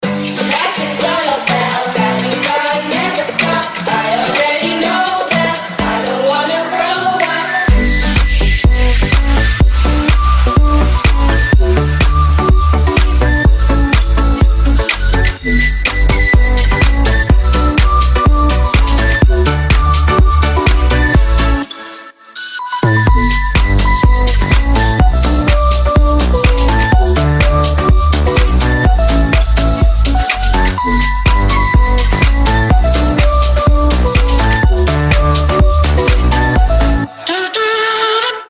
• Качество: 320, Stereo
позитивные
dance
EDM
house
легкие
Позитивная хаус-музыка.